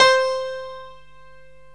PIANO 0005.wav